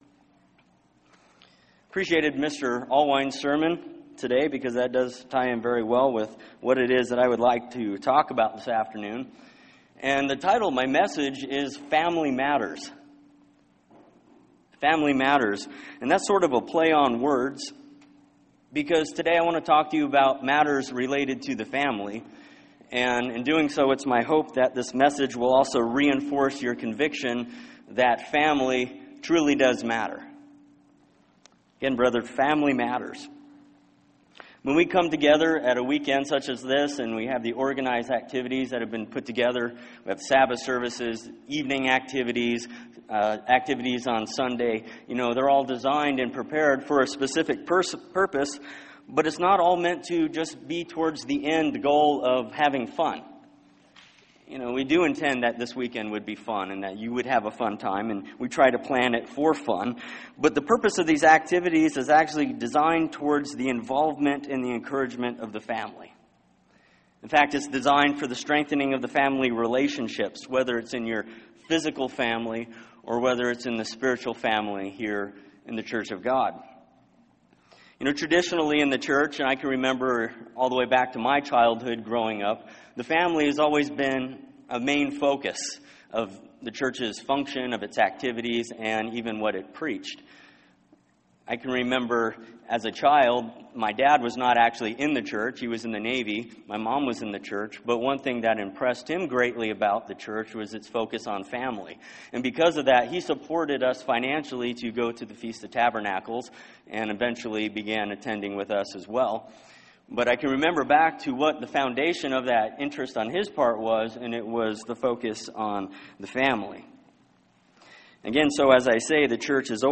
Second split sermon on Saturday, January 16, 2016 in Spokane, Washington God is building His spiritual family and our relationships with one another should reflect Godly characteristics.